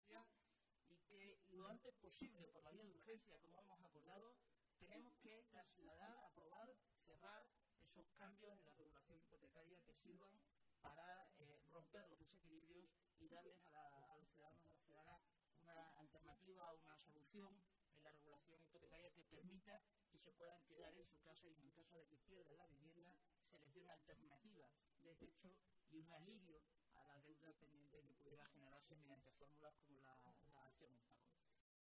Momento de la rueda de prensa anterior a las jornadas